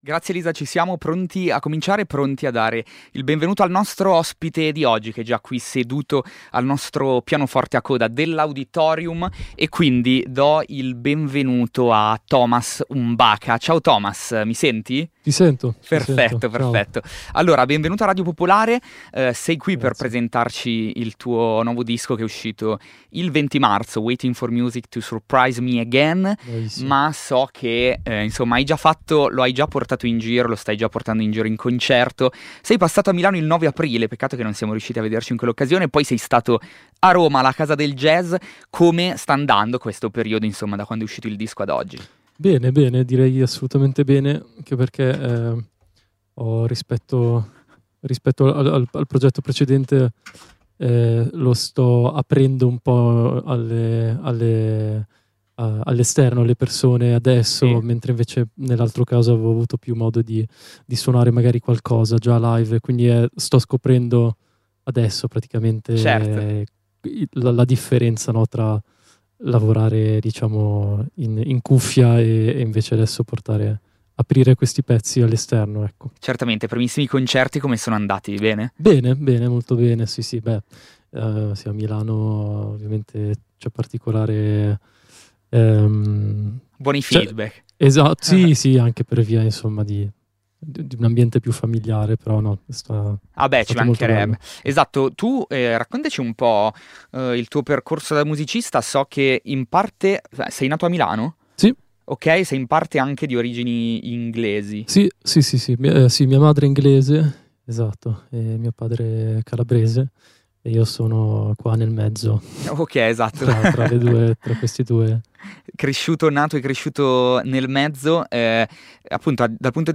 Un viaggio sonoro dove il pianoforte sfuma dalla sua dimensione classica per dialogare con synth elettronici, chitarre ed elementi vocali, abbracciando una dimensione ambient evocativa e cinematografica.
L'intervista